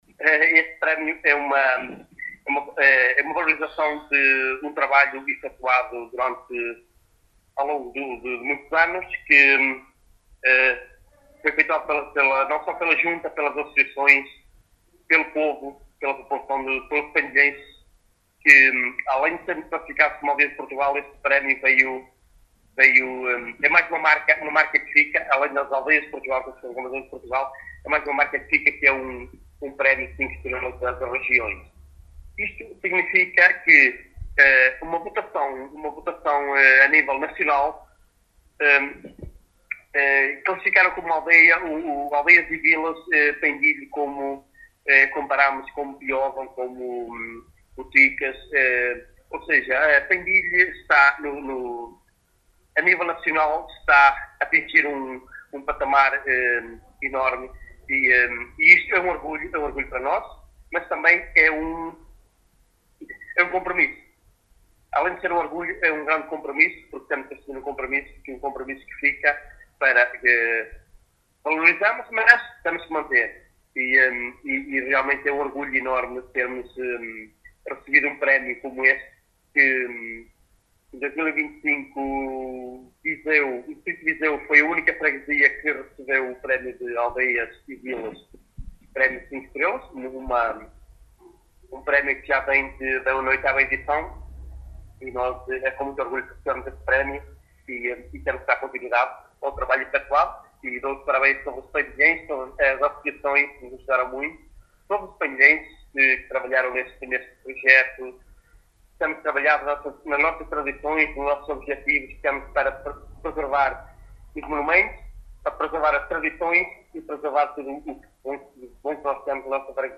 Jorge Cerdeira, Presidente da Junta de Freguesia de Pendilhe, em declarações à Alive FM, fala da importância deste prémio, “este prémio foi o culminar de um trabalho de vários anos…”.